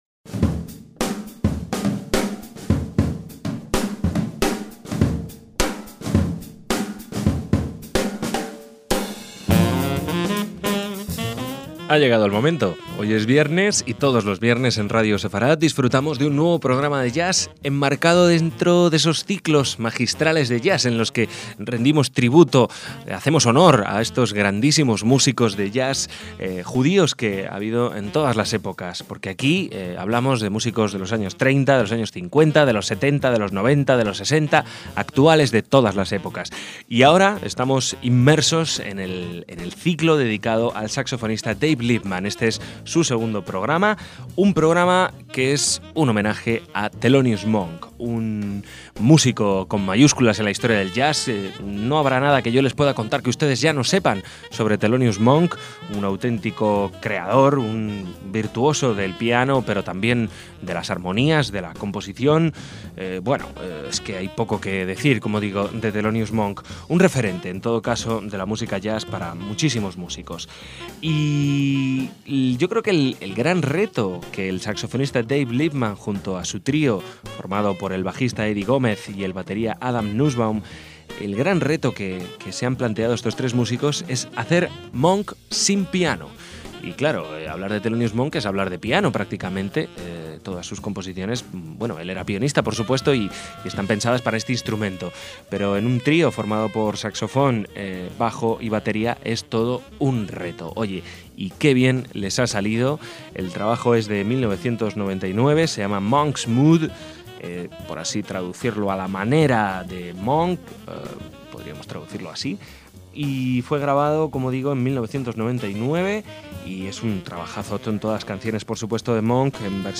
en trío